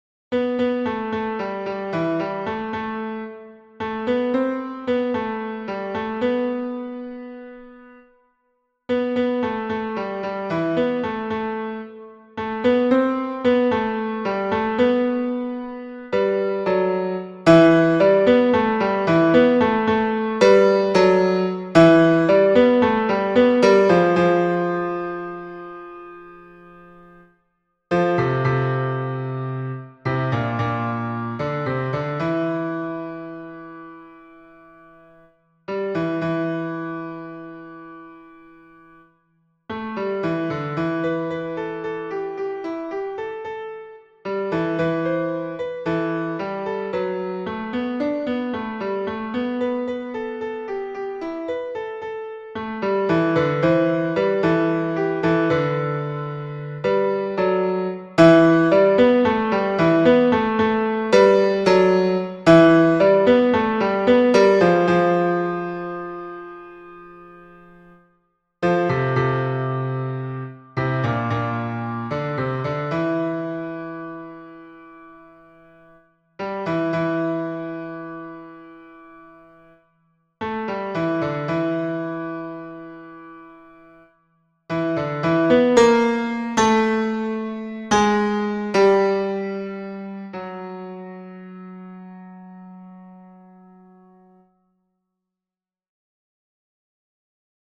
Hommes et autres voix en arrière-plan